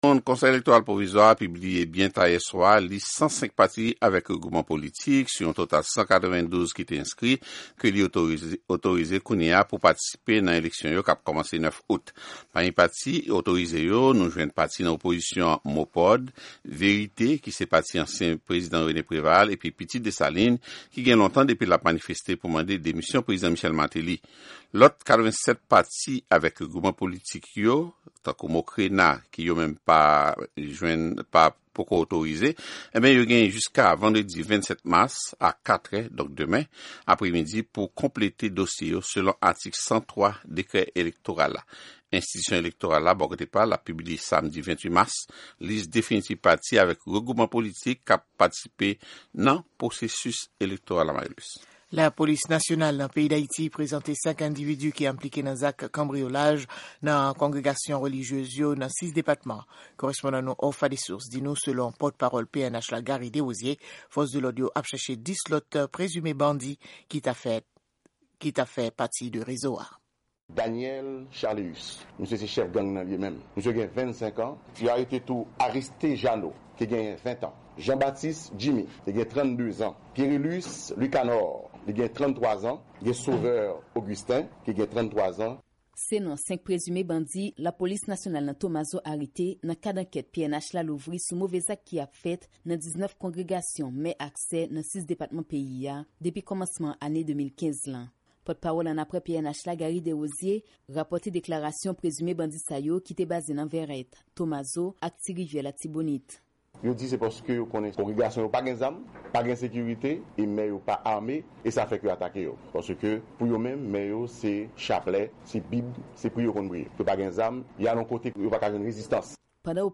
Konseye elektoral Néhémie Joseph, ki pale ak Sèvis Kreyòl Lavwadlamerik la, di pami rezon ki fè KEP a diskalifye pati politik yo, genyen pa egzanp absans yon siyati otorize, absans non pati a sou yon lis ki sòti nan ministè lajistis oubyen dokiman pati yo soumèt yo enkonplè.